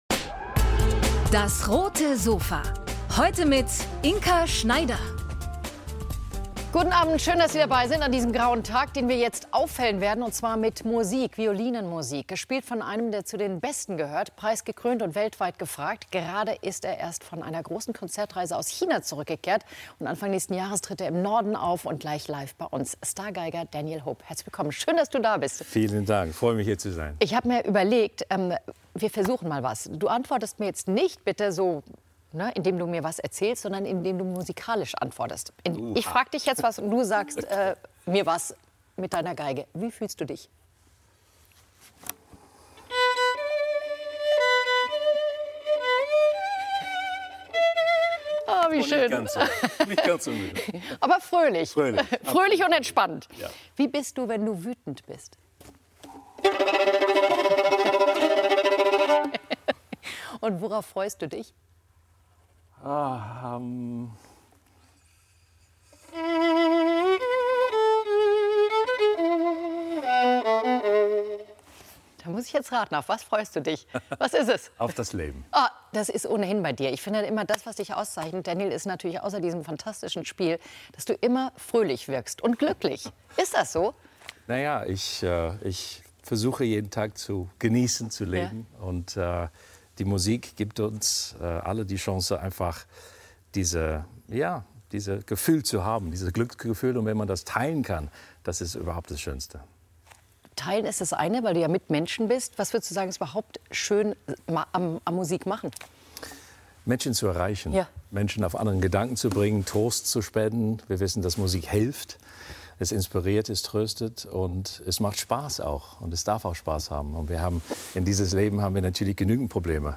Er gehört zur Weltelite der Klassik: Star-Geiger Daniel Hope ~ DAS! - täglich ein Interview Podcast